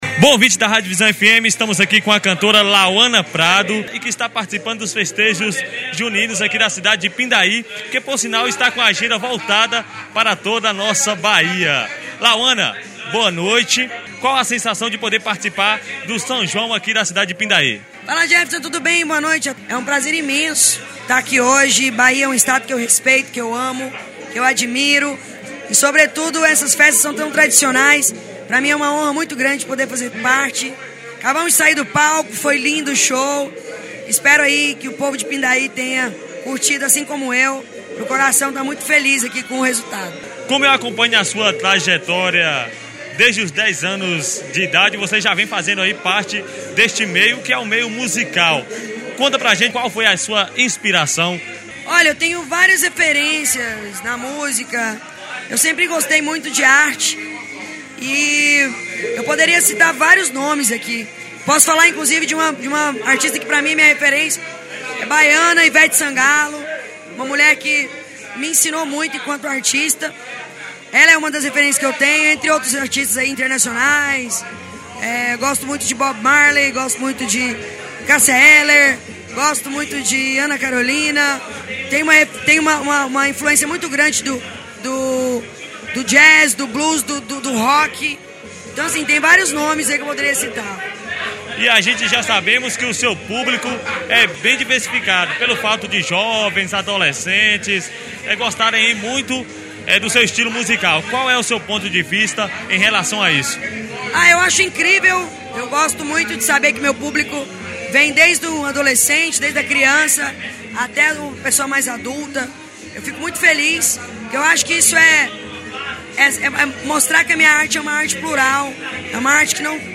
Portal Vilson Nunes faz cobertura do 'São João de Pindaí' e entrevista cantora Lauana Prado; ouça - Portal Vilson Nunes